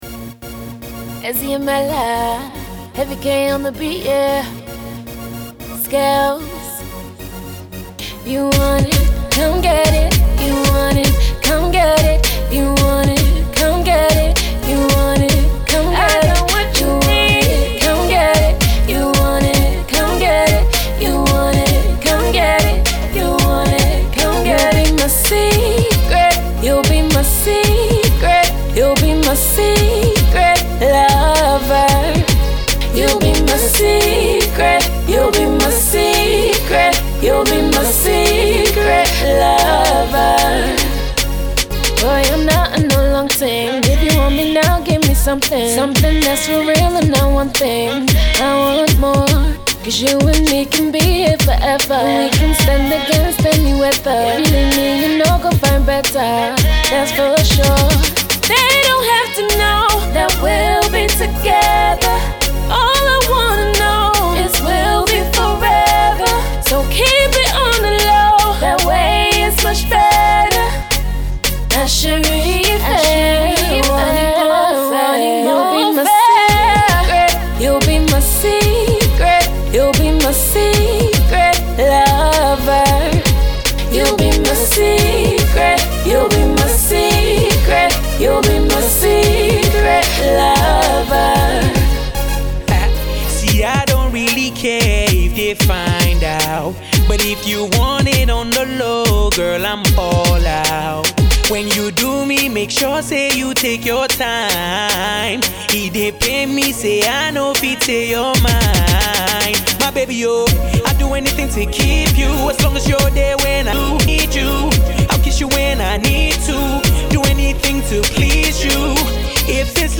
UK Afrobeats